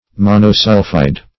Search Result for " monosulphide" : The Collaborative International Dictionary of English v.0.48: Monosulphide \Mon`o*sul"phide\, n. [Mono- + sulphide.]